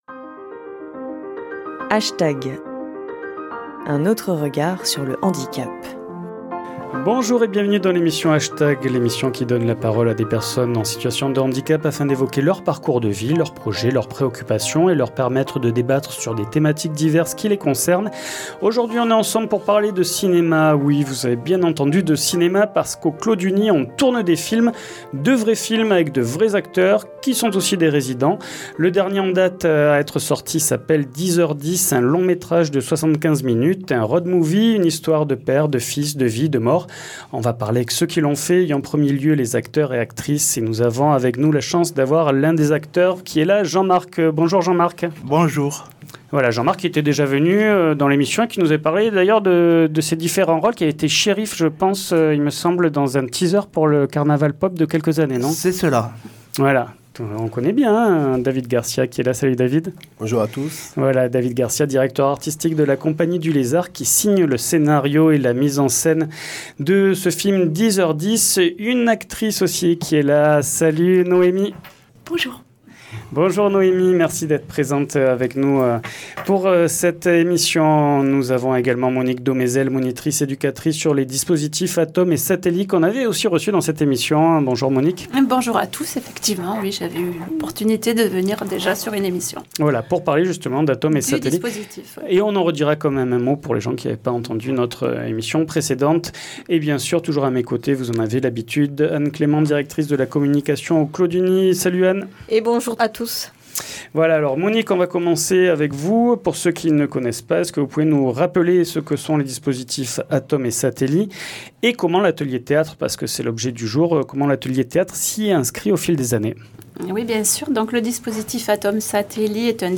H Tag ! donne la parole à des personnes en situation de handicap ou des personnes en situation de vulnérabilité afin d’évoquer leurs parcours de vie, leurs projets, leurs préoccupations, et leur permettre de débattre sur des thématiques diverses qui les concerne